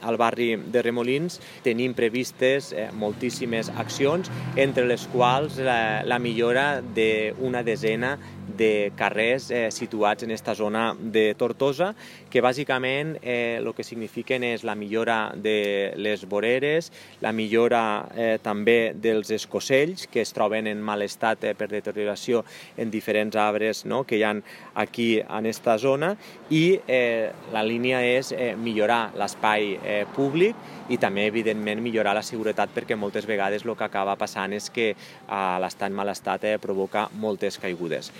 Jordi Jordan, alcalde de Tortosa…